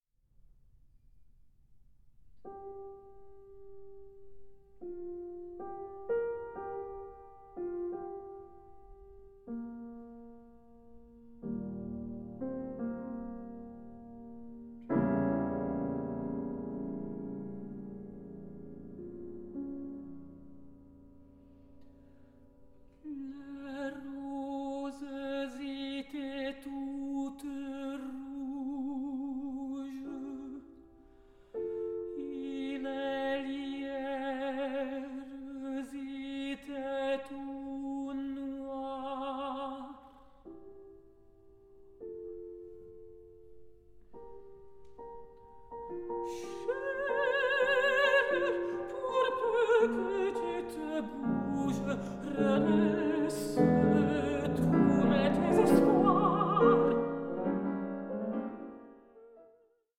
mezzo-soprano
pianist